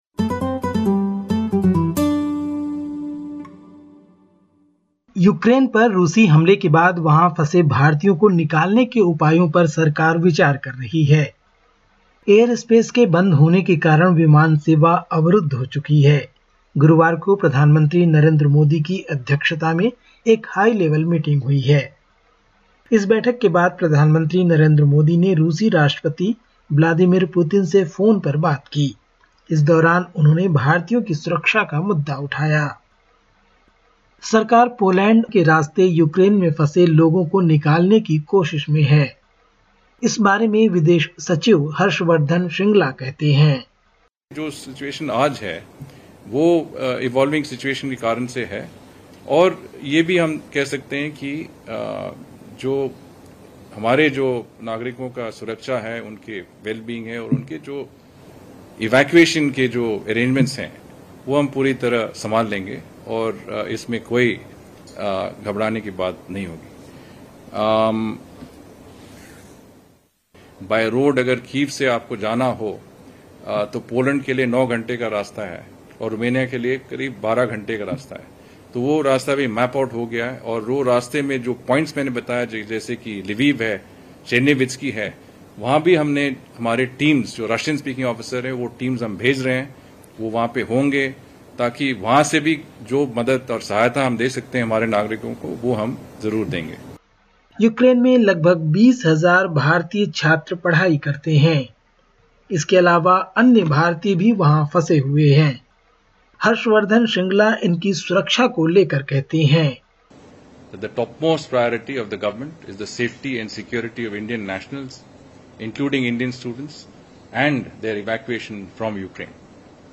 Listen to the latest SBS Hindi report from India. 25/02/2022